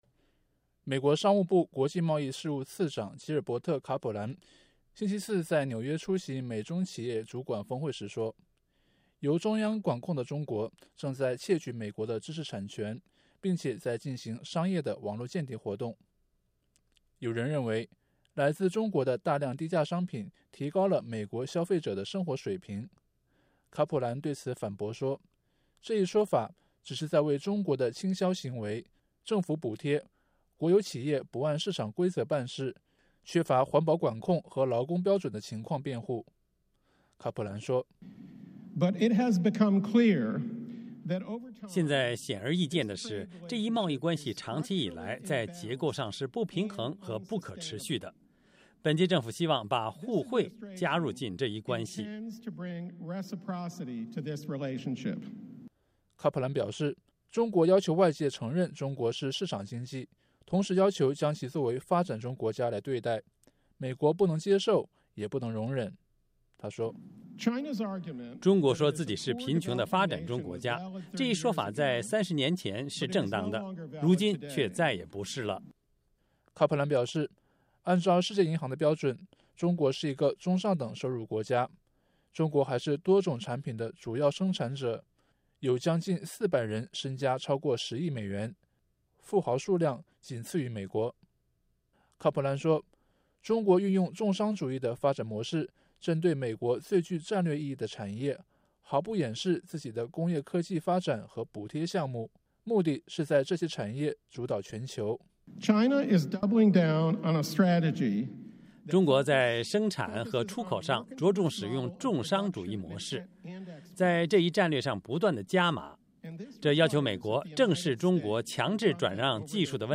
美国商务部国际贸易事务次长吉尔伯特·卡普兰4月13日在纽约讲话